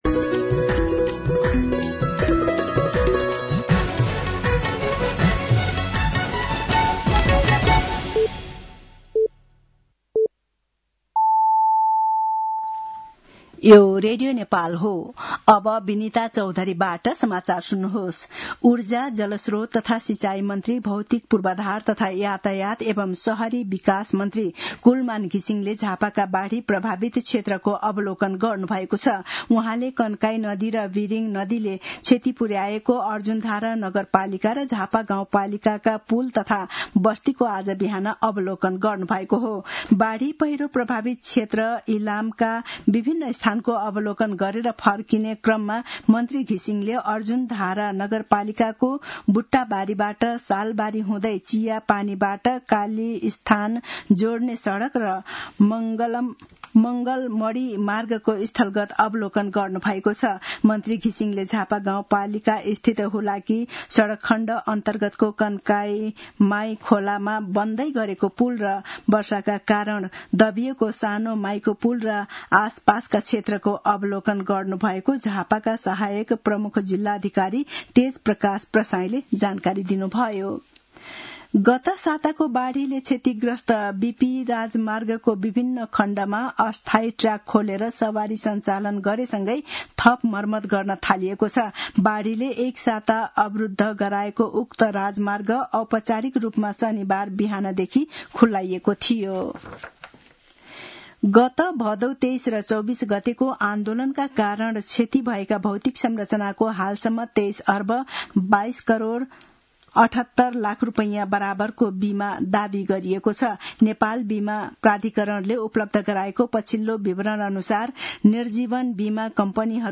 साँझ ५ बजेको नेपाली समाचार : २६ असोज , २०८२
5pm-news-6-26.mp3